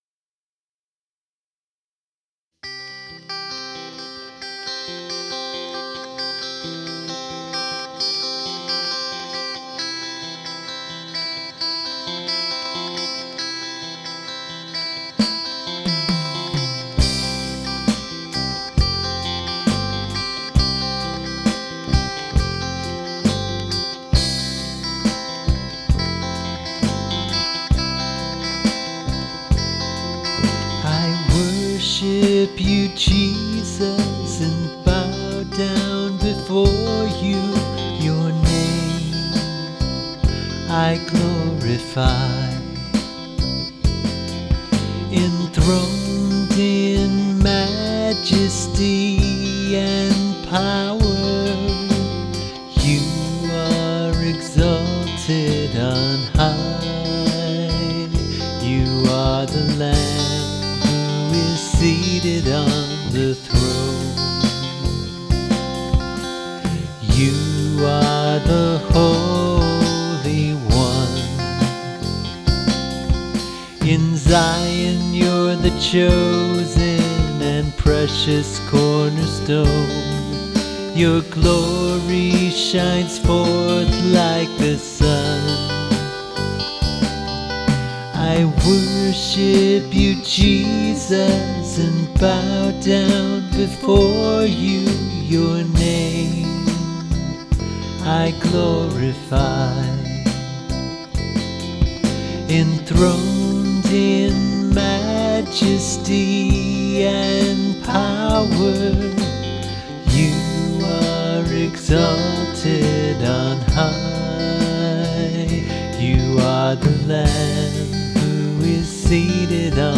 Vocals, Guitars, Bass
Lead Guitar
Drums